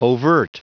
Prononciation du mot overt en anglais (fichier audio)
Prononciation du mot : overt